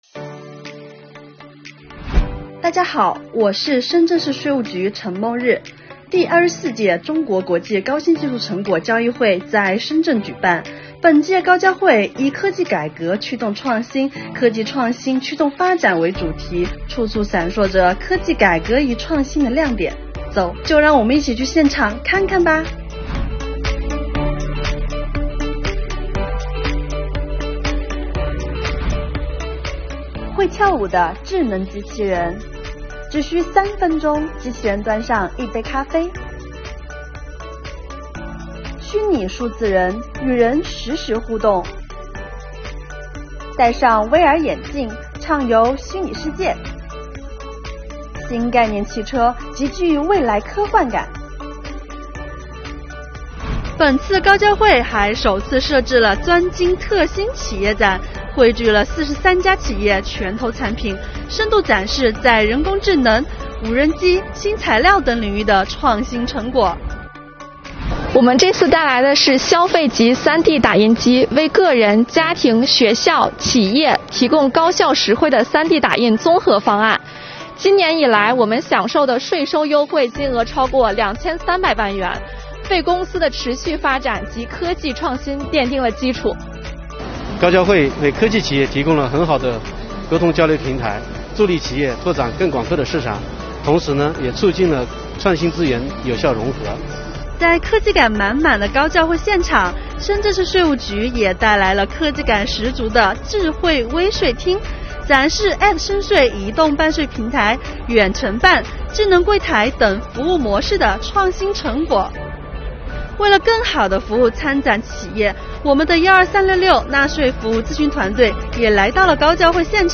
近日，第24届中国国际高新技术成果交易会在深圳举办，本届高交会以“科技改革驱动创新，科技创新驱动发展”为主题，现场各种新奇、科幻、未来感十足的“黑科技”纷纷亮相，处处闪烁着科技改革与创新的亮点。今天，税务小姐姐带您沉浸式探馆，一起玩转“黑科技”！